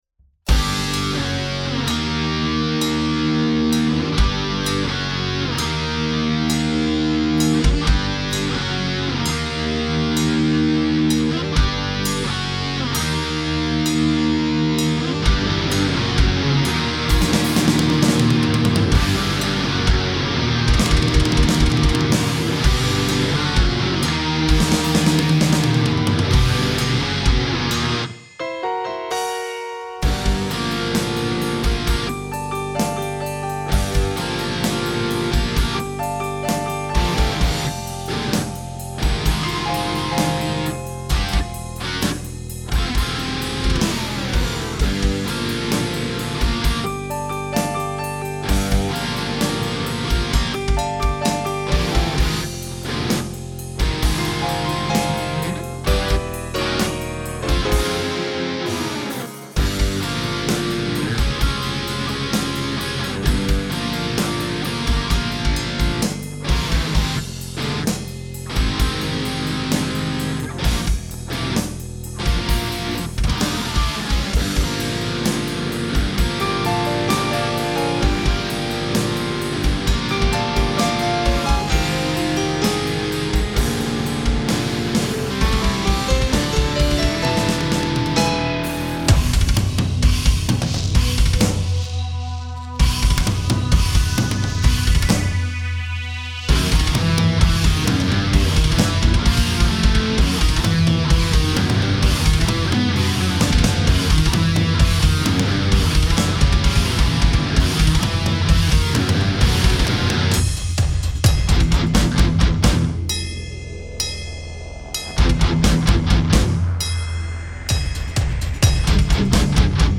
Это пока черновик, без кучи партий, естественно, не претендующий на какие-то оценки с точки зрения техники, но оценить масштаб трагедии "аккорды плохо звучат" в гитарах вполне можно. Там, пожалуй, на всю тему только две квинты, остальное всякие 7ки и прочее.